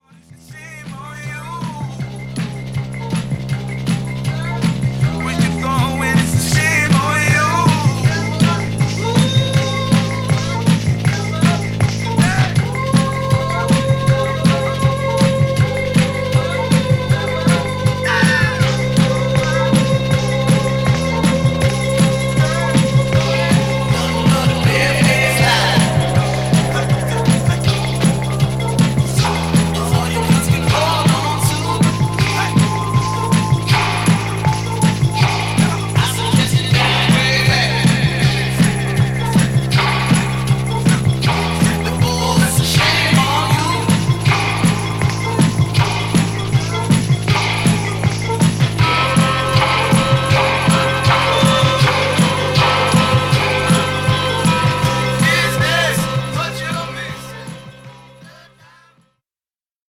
スコットランドのプログレッシブ・ヒップホップ・グループ